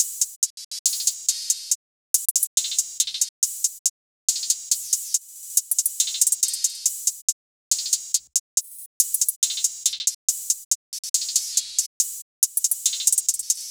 drumloop 7 (140 bpm).wav